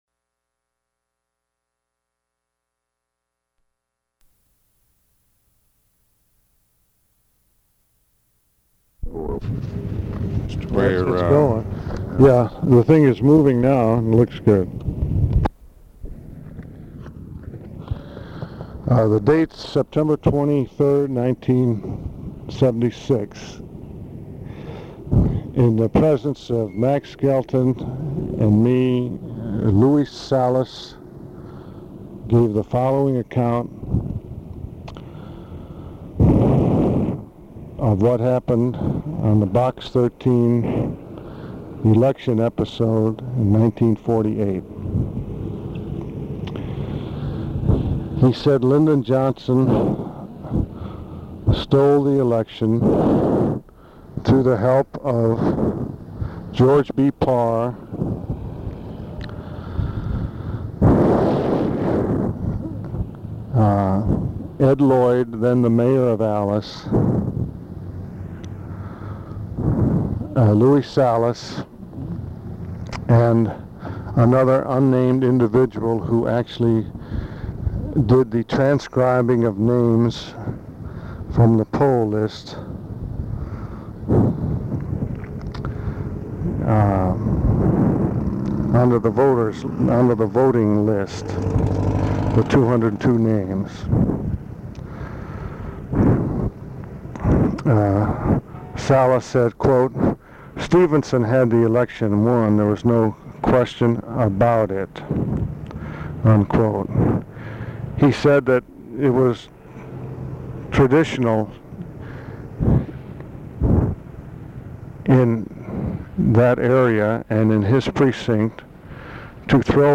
side A Archivist General Note Three apparently unrelated segments, segments 2 and 3 are incomplete. Second has poor audio quality.
Interview, Press briefing